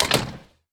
door sound
door.wav